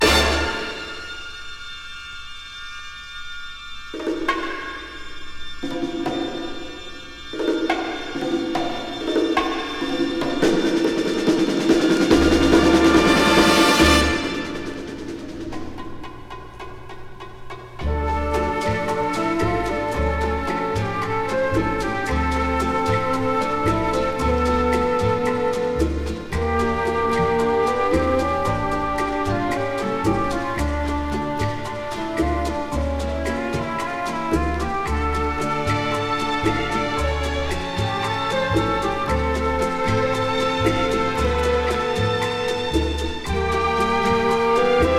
本盤で取り組んだのはキューバ音楽。